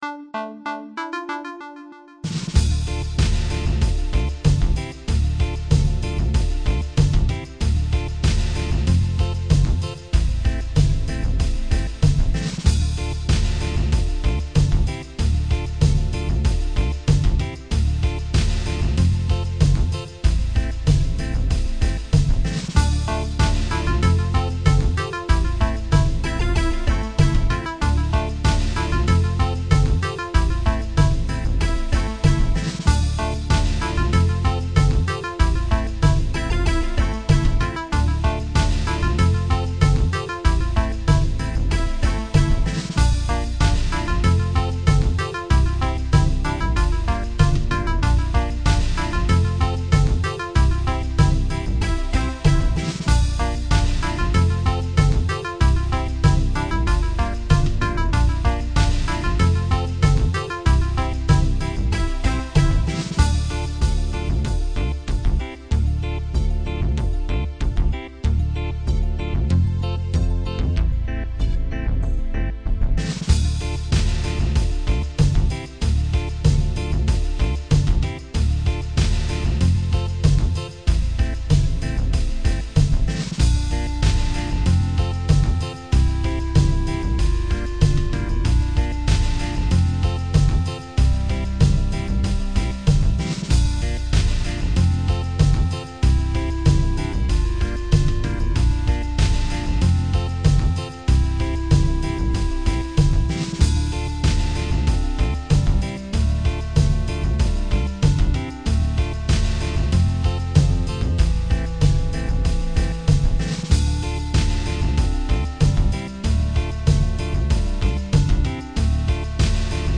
Downtempo relaxing track with a slightly uplifting feel.
• Music requires/does smooth looping